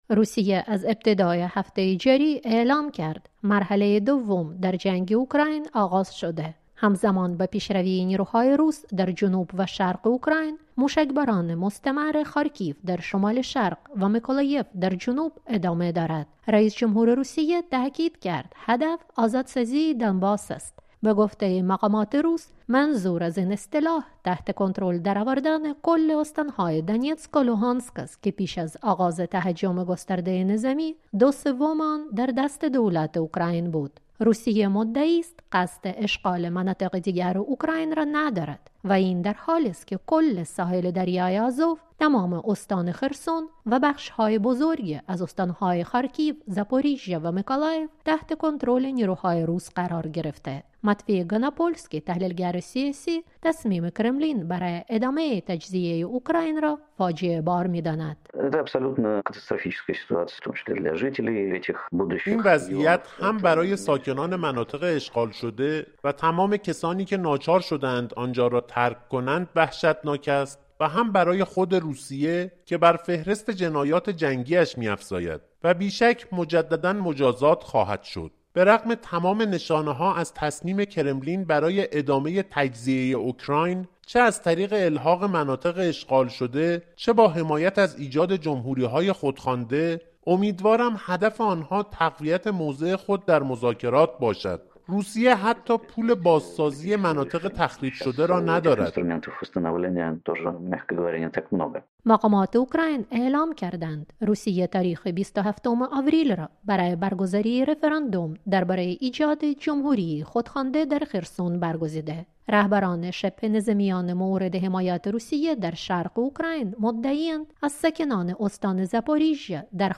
کی‌یف معتقد است هدف مسکو، تصرف کامل استان‌های جنوب و شرق اوکراین و ضمیمه کردن این مناطق به خاک روسیه برای ایجاد ارتباط زمینی با کریمه الحاقی است. گزارشی در این زمینه